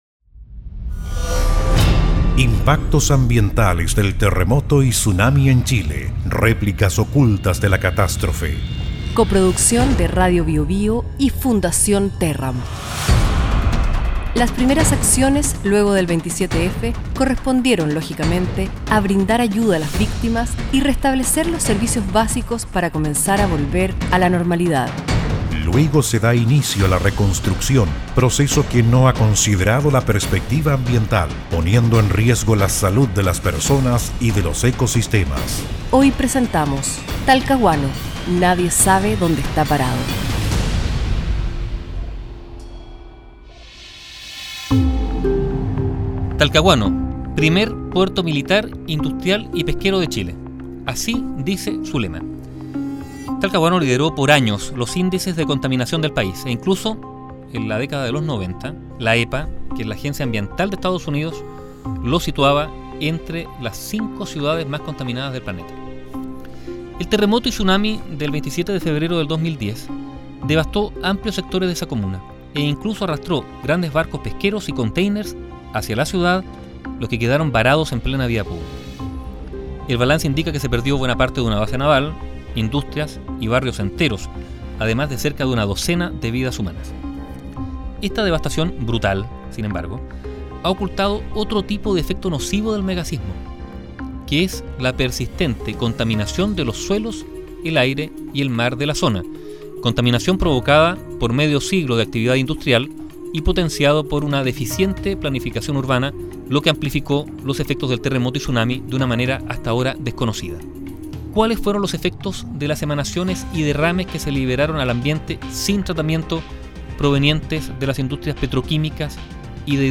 Escucha aquí los reportajes radiales sobre las consecuencias socioambientales del terremoto y tsunami del 27 de febrero en nuestro país.